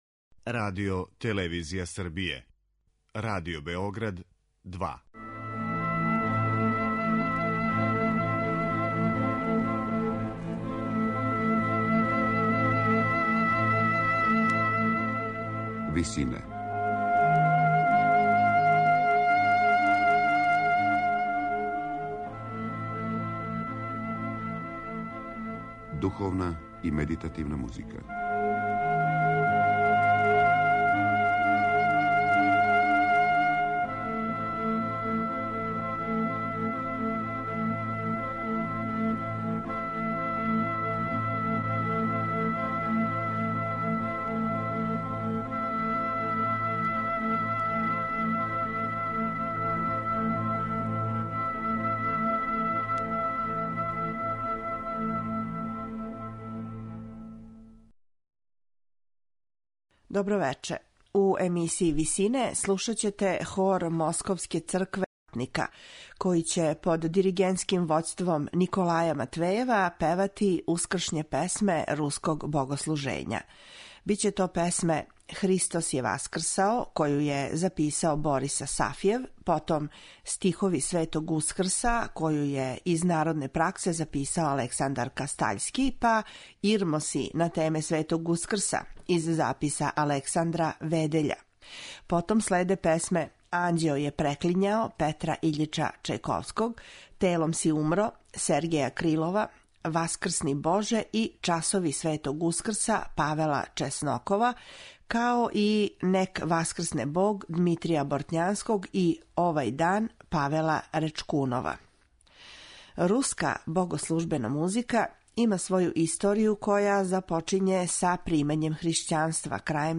Ускршње песме руског богослужења
медитативне и духовне композиције